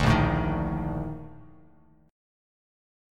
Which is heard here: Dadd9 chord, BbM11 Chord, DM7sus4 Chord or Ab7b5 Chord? BbM11 Chord